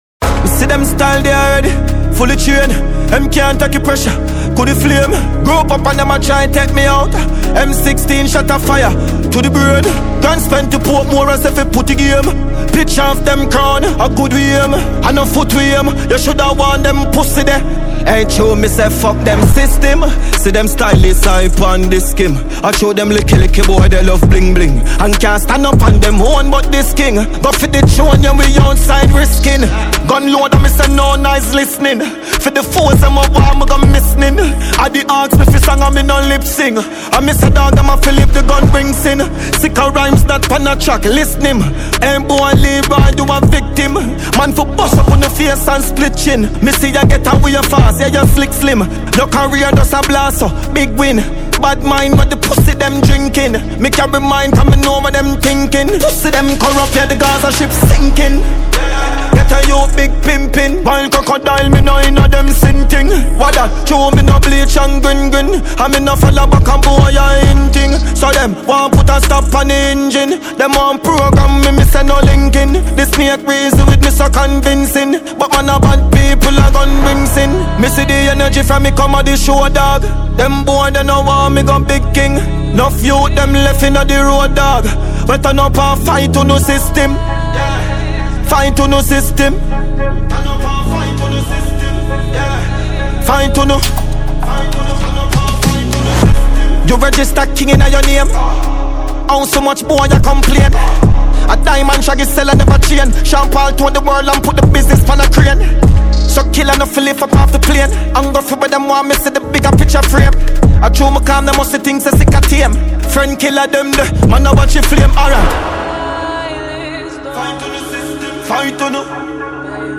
Dancehall
• Genre: Dancehall